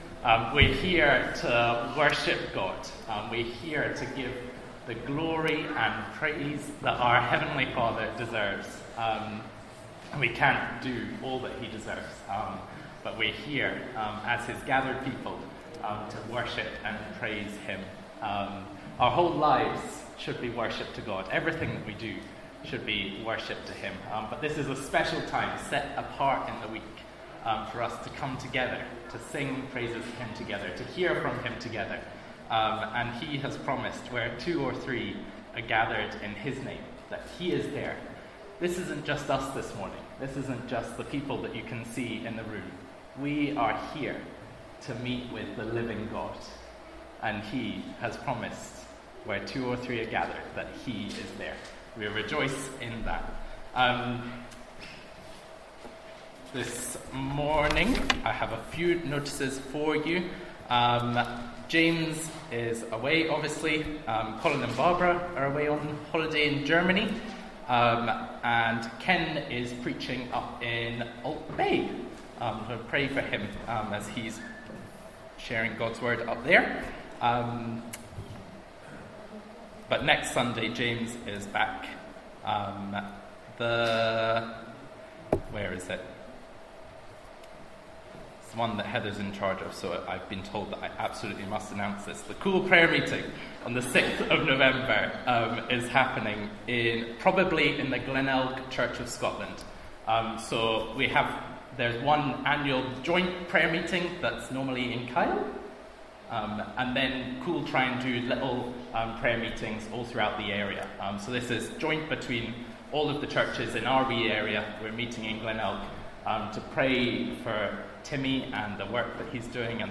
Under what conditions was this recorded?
Sunday Service 27th November